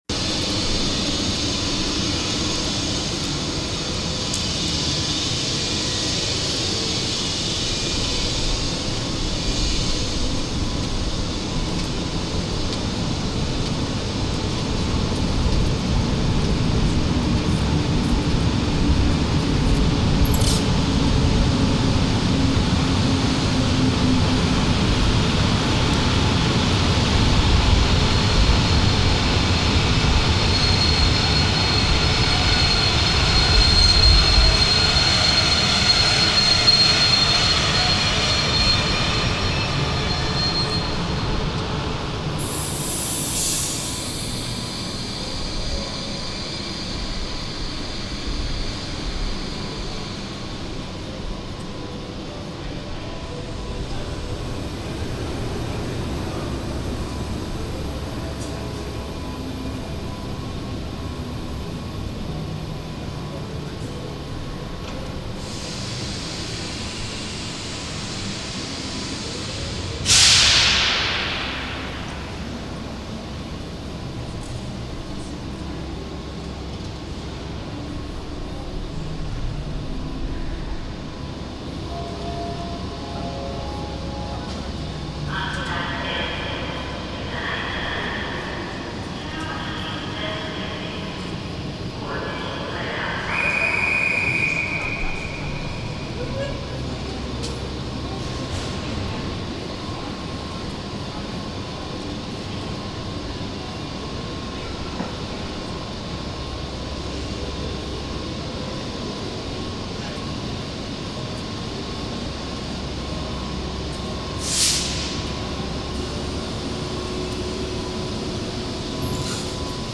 soundscapes, minimal-house, and electro-beats